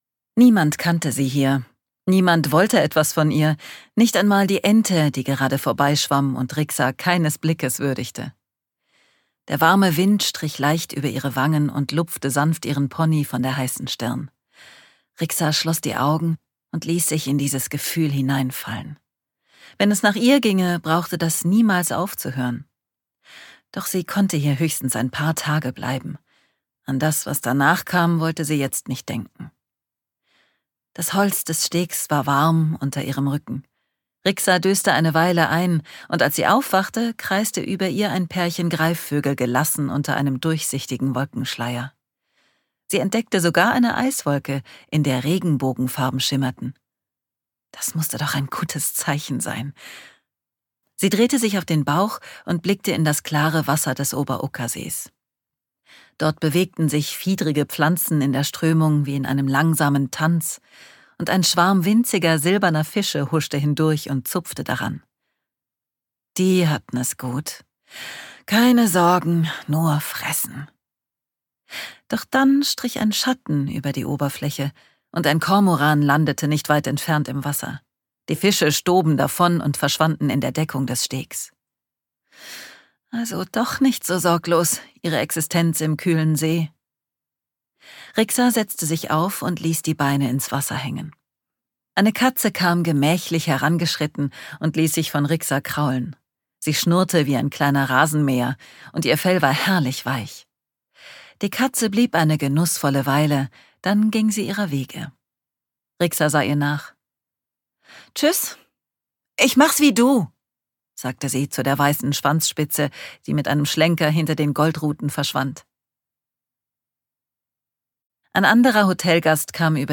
Wohin die Wünsche fließen - Patricia Koelle | argon hörbuch
Gekürzt Autorisierte, d.h. von Autor:innen und / oder Verlagen freigegebene, bearbeitete Fassung.